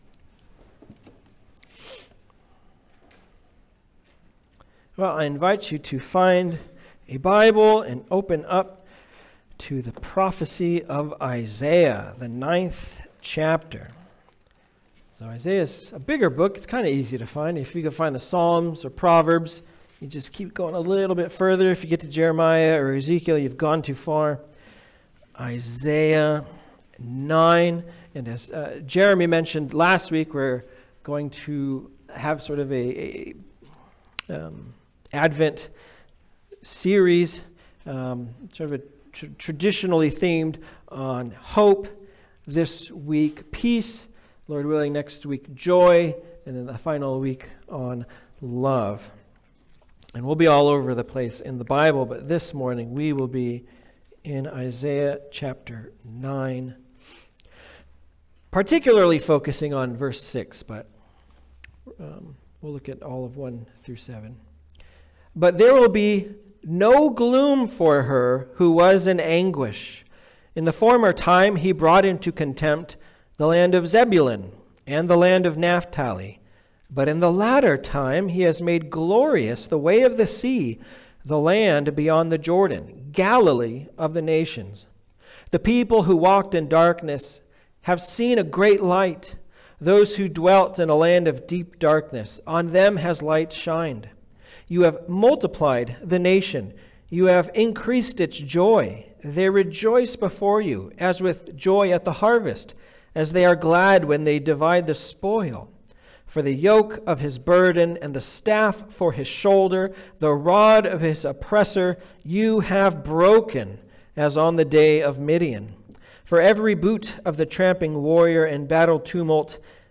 Isaiah 9:1-7 Service Type: Sunday Service « The God Who Restores Hope